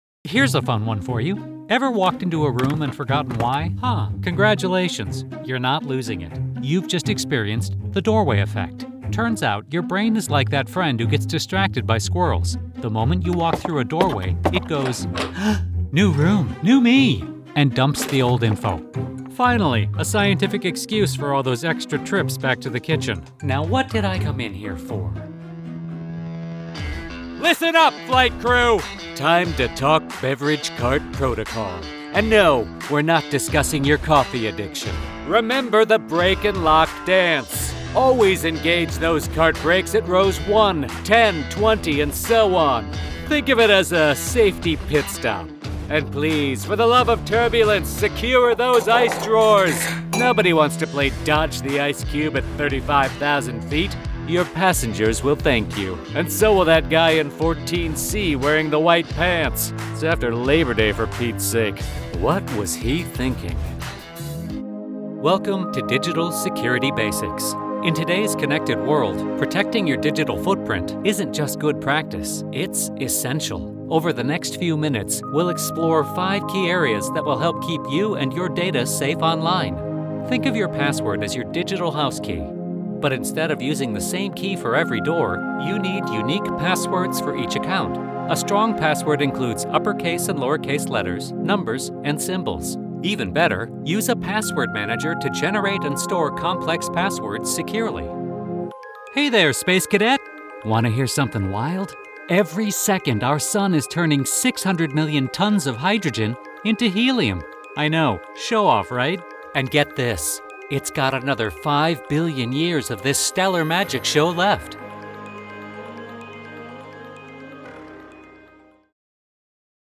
Anglais (Américain)
Commerciale, Naturelle, Amicale, Distinctive, Enjouée
Vidéo explicative
Friendly, warm, funny, guy next door sound.